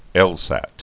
(ĕlsăt)